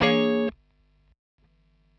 Bbm7_2.wav